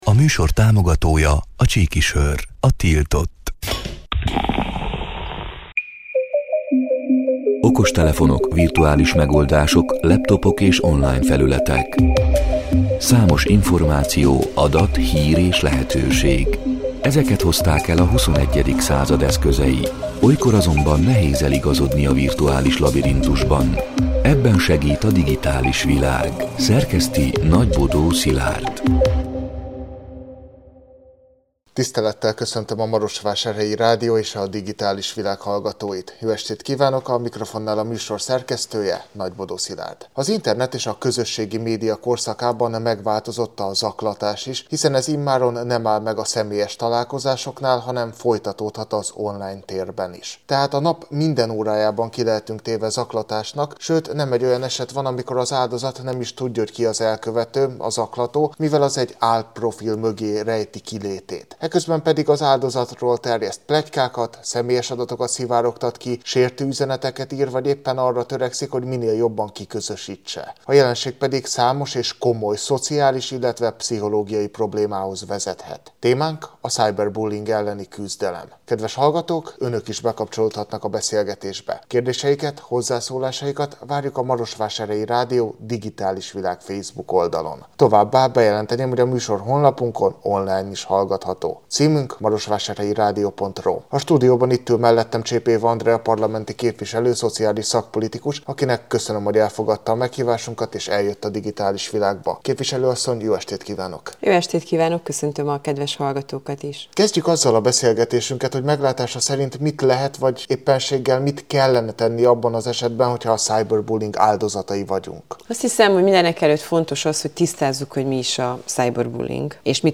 A Marosvásárhelyi Rádió Digitális Világ (elhangzott: 2024. október 29-én, kedden este nyolc órától) c. műsorának hanganyaga: